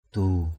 /d̪u:/ 1.